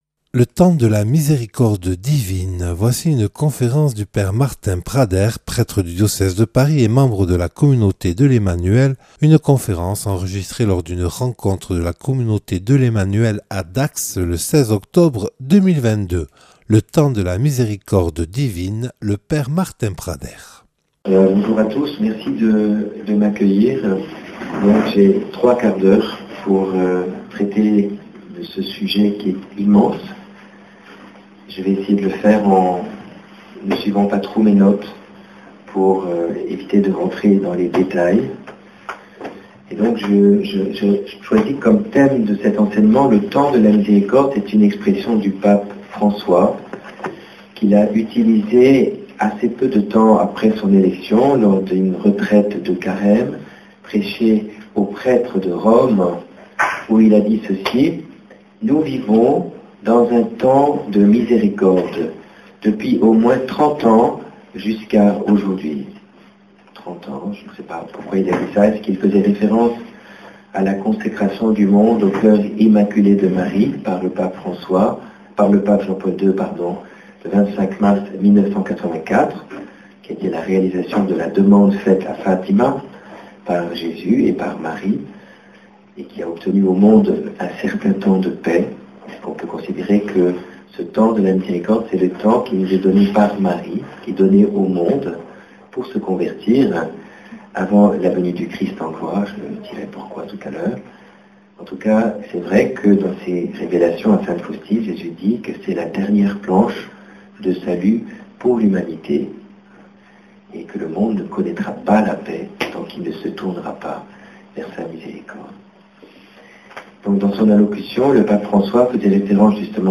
Une conférence
(Enregistré le 16/10/2022 à Dax lors d’une rencontre de la Communauté de l’Emmanuel).